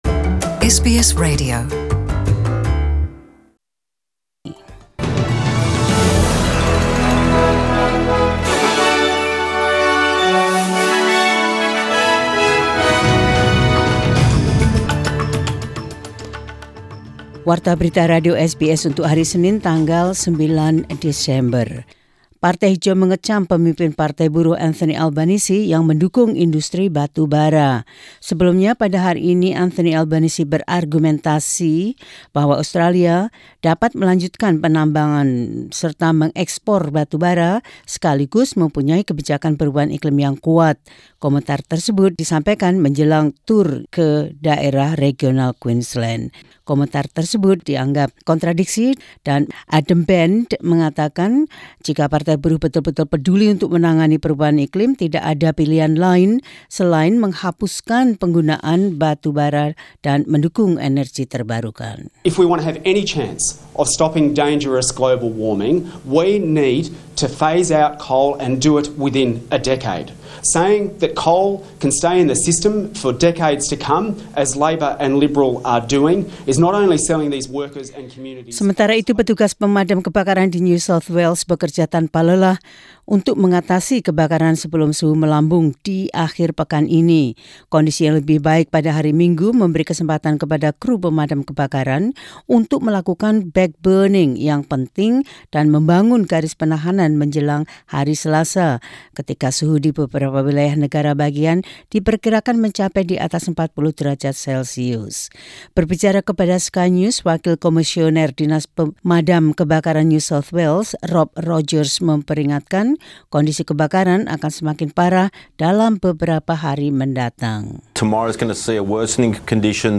SBS Radio News in Indonesian - 09 Dec 2019.